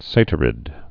(sātər-ĭd, sătər-, sə-tīrĭd)